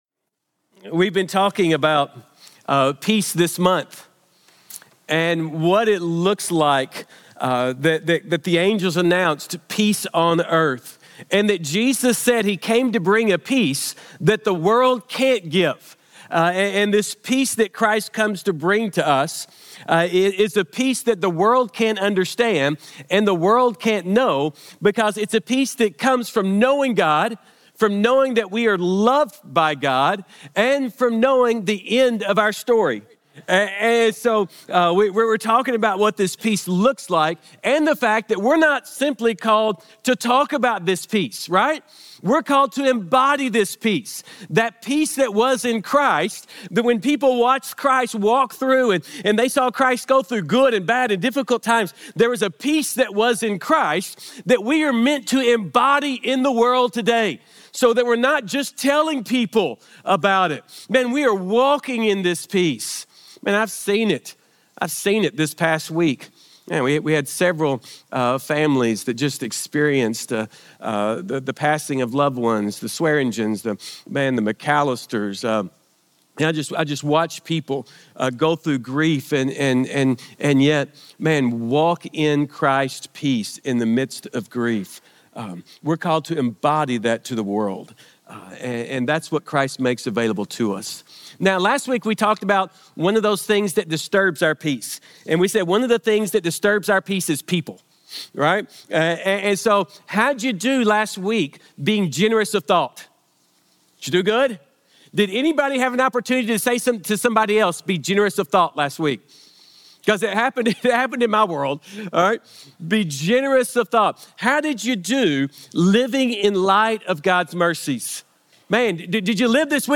Sermons | Grace Community Church